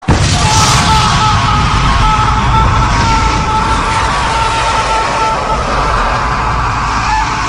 Vegeta S Scream